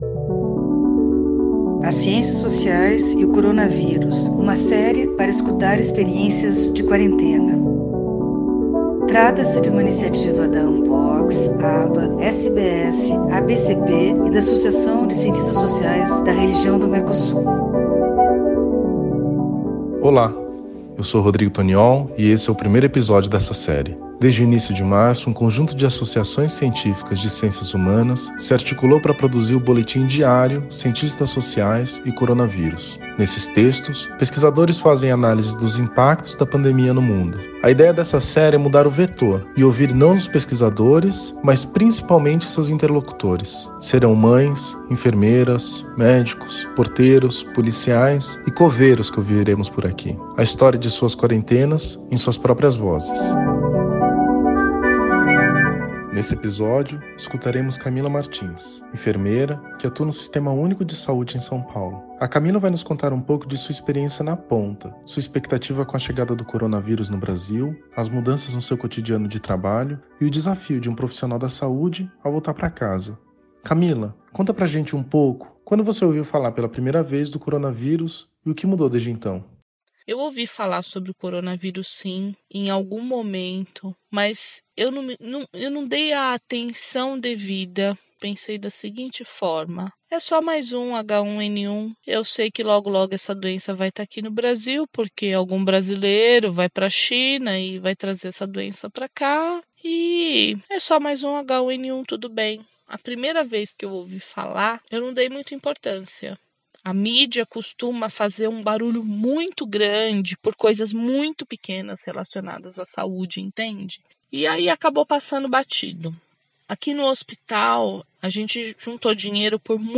O Boletim Cientistas Sociais e o Coronavírus apresenta o seu primeiro Podcast - Episódio 1: Profissionais de Saúde, com o relato de uma enfermeira sobre sua experiência durante a pandemia no Brasil.
CSO_e_coronavirus_Enfermeira_de_SP.mp3